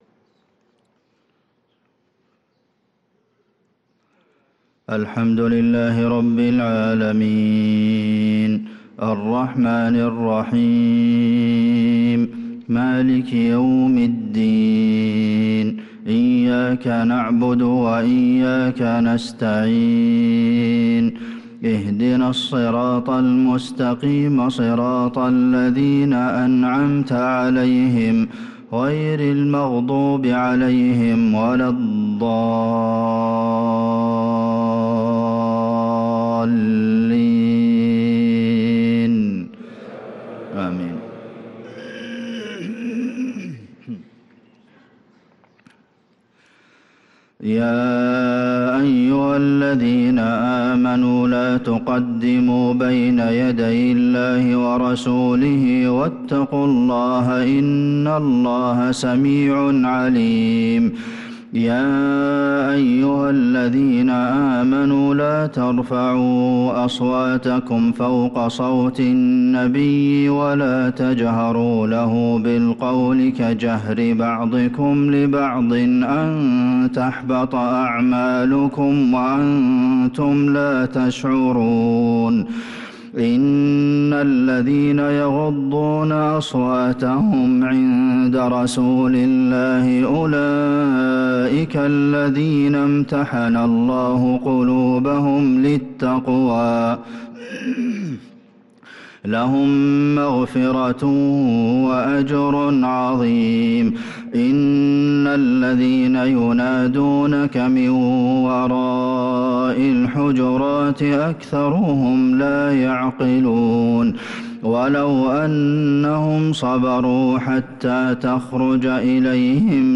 صلاة الفجر للقارئ عبدالمحسن القاسم 23 رجب 1445 هـ